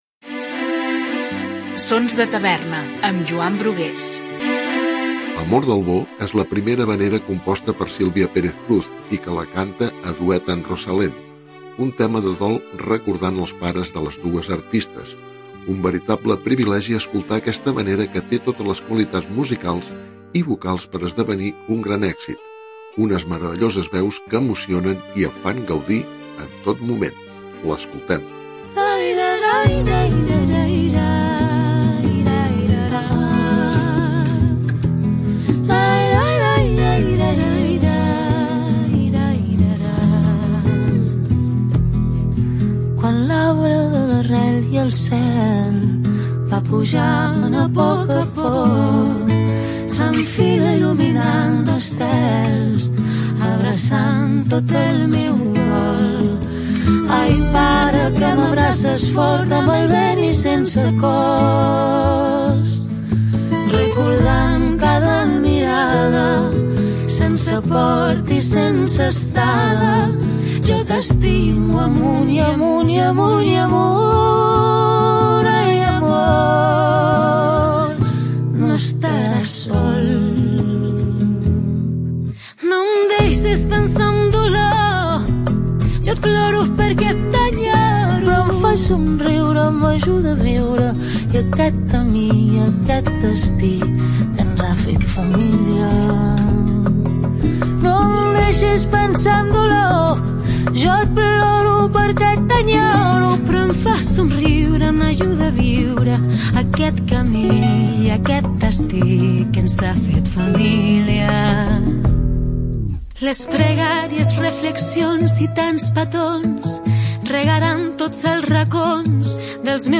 havanera